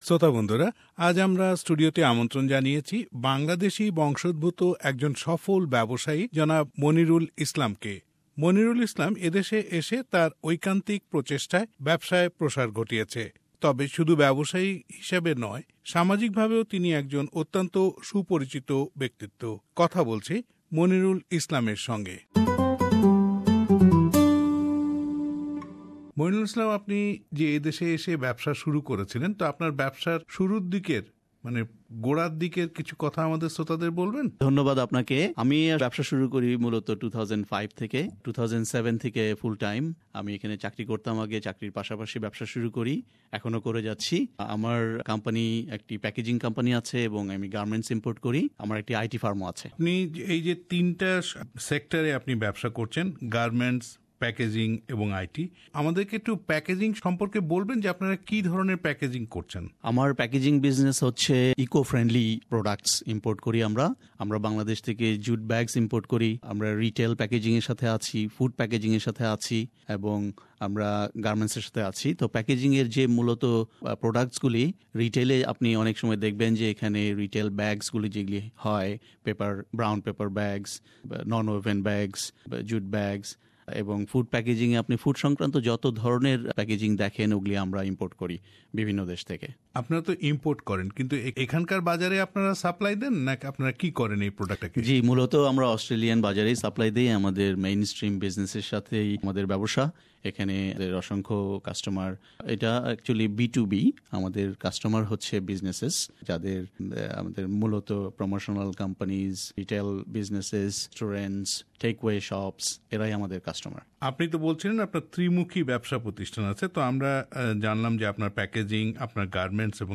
Small Business Secret : Interview